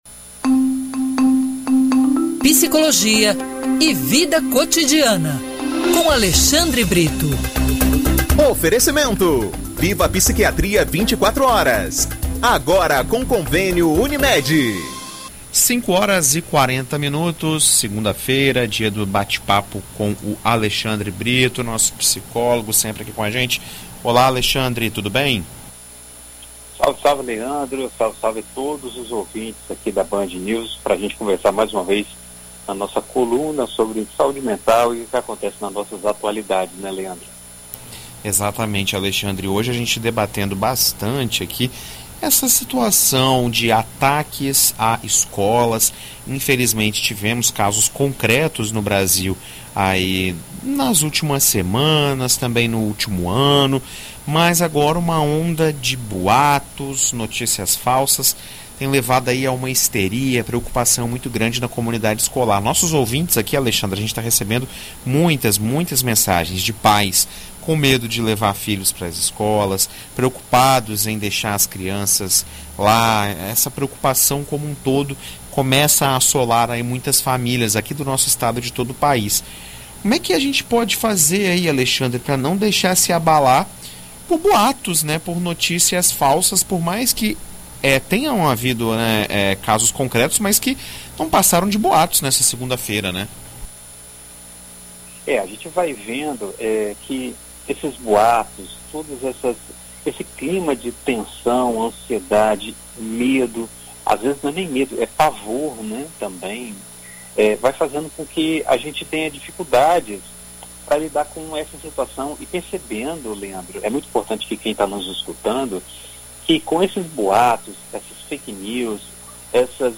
Devido ao alto número de ataques a escolas recentemente, uma onda de notícias falsas vem sendo propagadas em relação a ameaças de novos massacres. Em entrevista à BandNews FM ES nesta segunda-feira